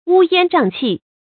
注音：ㄨ ㄧㄢ ㄓㄤˋ ㄑㄧˋ
烏煙瘴氣的讀法